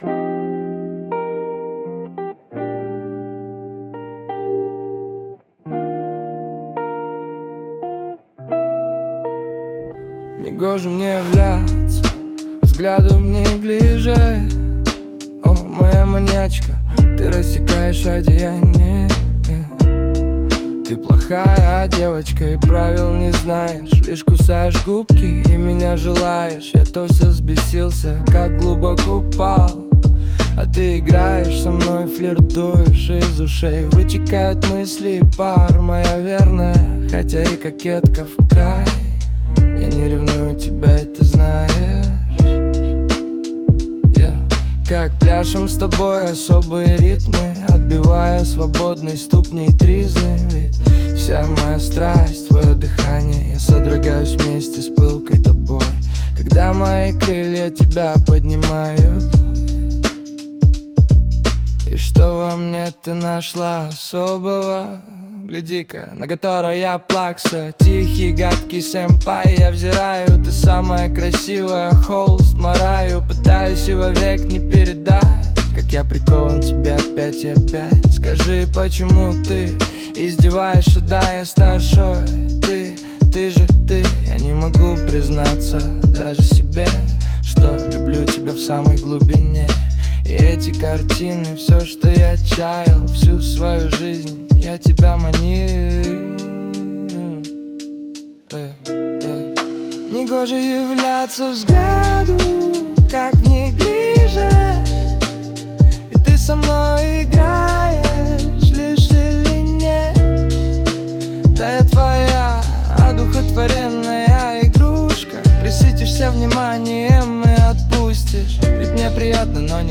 Музыка и исполнение принадлежит ИИ.
ТИП: Пісня
СТИЛЬОВІ ЖАНРИ: Романтичний